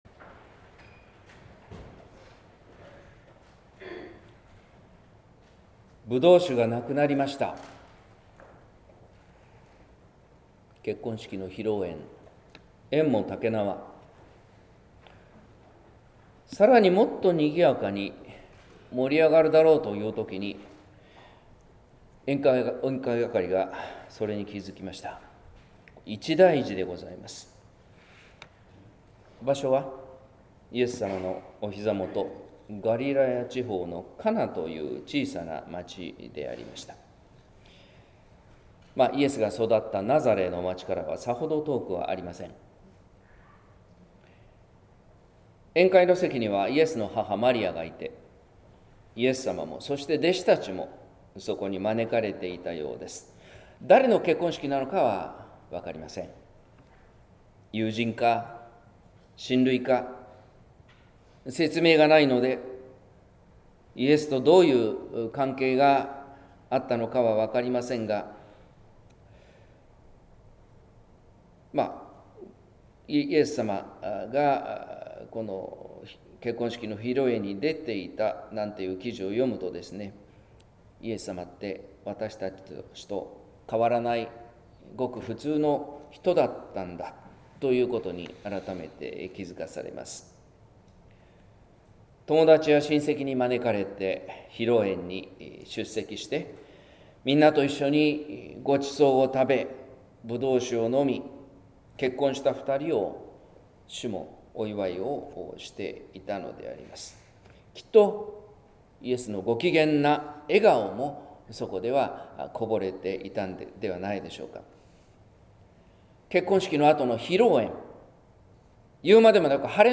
説教「舞台裏の奇跡」（音声版）